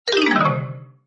lose.mp3